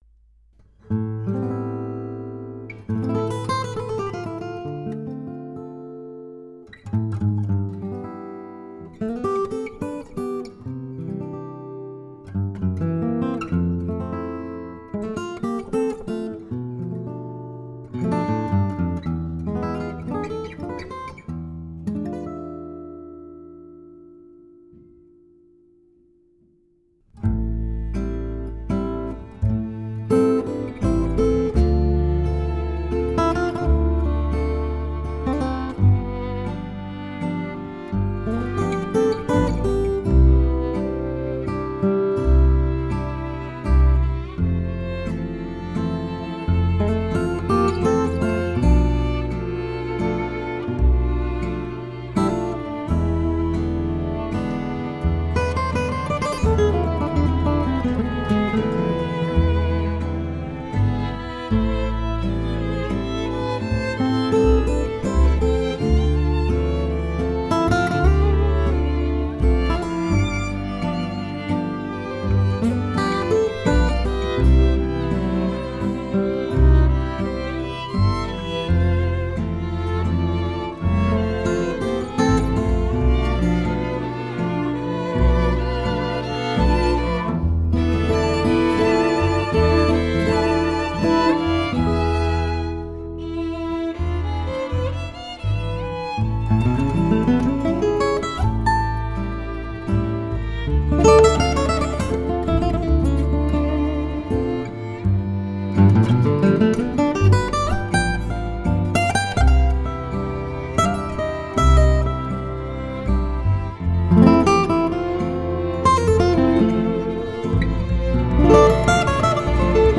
He had previously recorded the backup at Fantasy Studios, played by the wonderful Quartet San Francisco. He came about 11am, borrowed a classic size steel string cutaway, and returned by 3:30 with the guitar and a first mix with both tracks.